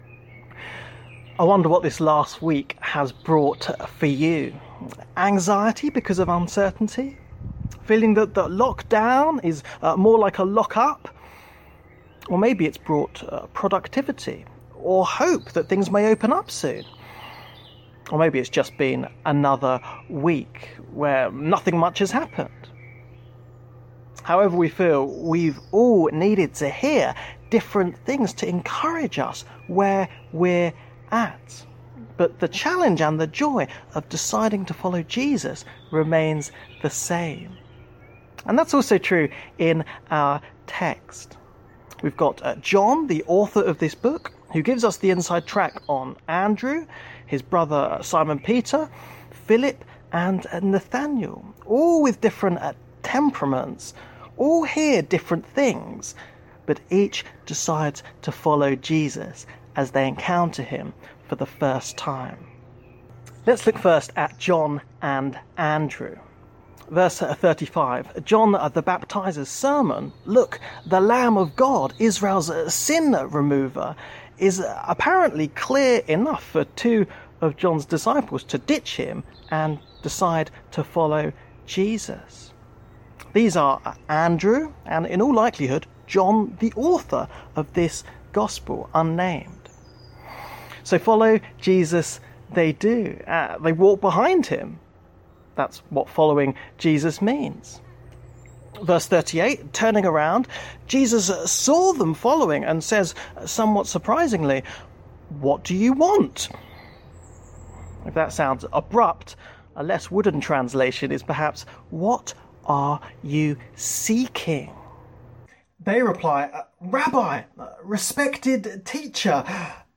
Bible Text: John 1 : 35 – 51 | Preacher